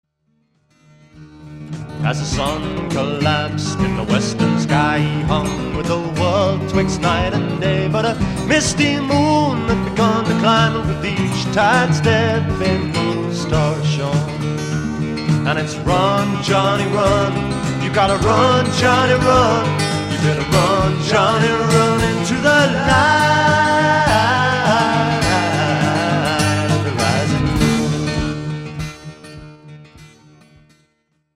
This is a complex piece time-wise